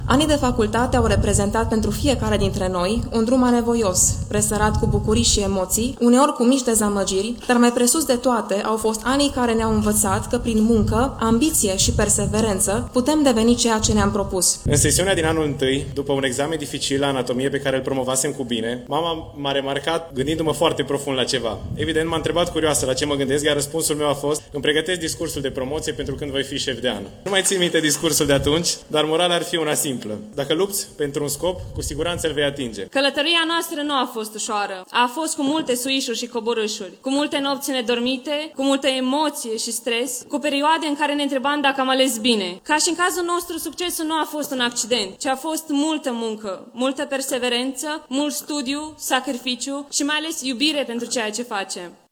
Lecții de viață la festivitatea de absolvire a UMFST Târgu Mureș
Aproape 2000 de absolvenți ai Universității de Medicină, Farmacie, Științe și Tehnologie ”George Emil Palade” din Târgu Mureș au pornit în marș de la facultățile lor spre Stadionul Transil, unde a fost organizată festivitatea de absolvire.
Șefii de promoții au fost invitați să le adreseze colegilor câteva cuvinte.